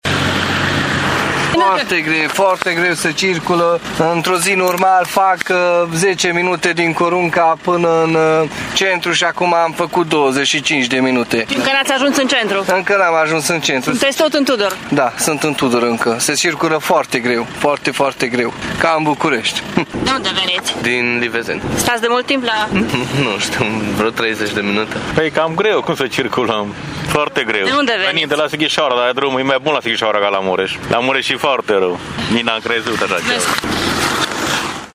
Șoferii sunt nevoiți să se încarce cu multă răbdare pentru a traversa orașul, deoarece astăzi se circulă ca în București, spun aceștia: